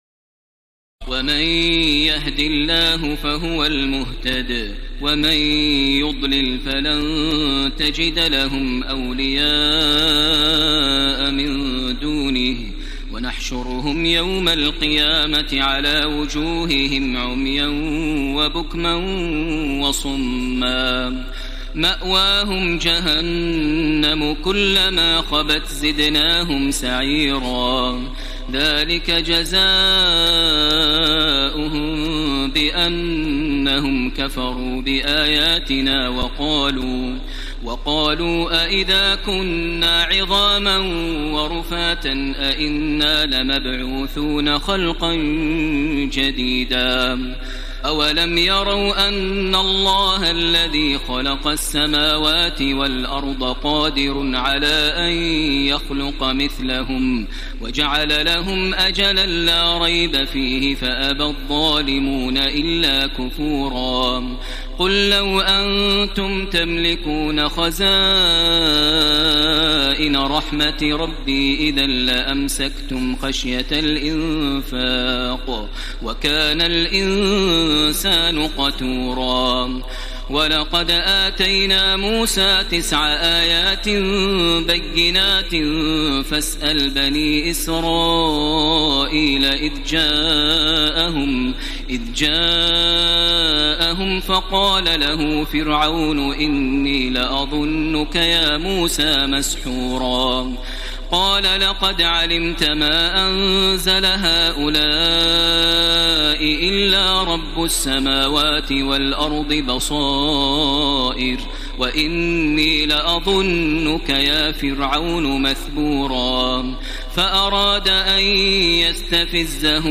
تراويح الليلة الرابعة عشر رمضان 1434هـ من سورتي الإسراء (97-111) والكهف (1-82) Taraweeh 14 st night Ramadan 1434H from Surah Al-Israa and Al-Kahf > تراويح الحرم المكي عام 1434 🕋 > التراويح - تلاوات الحرمين